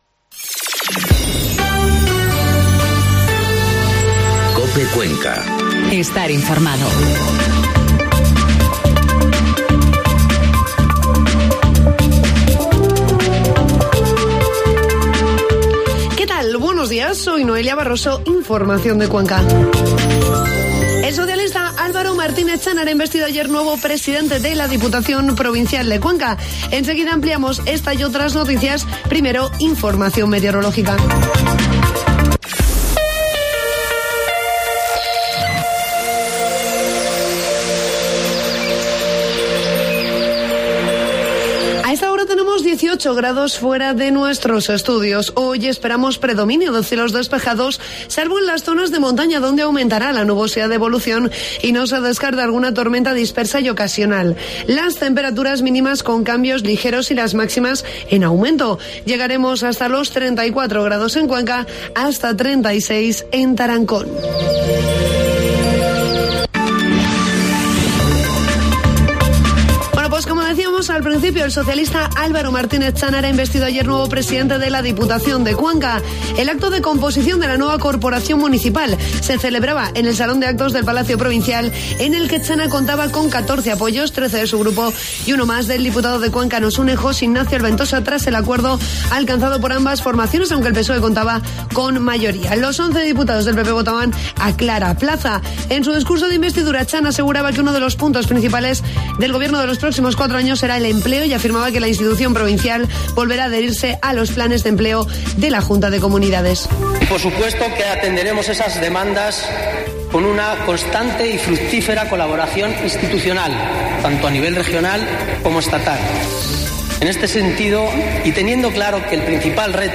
Informativo matinal COPE Cuenca 5 de julio